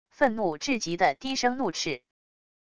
愤怒至极的低声怒斥wav音频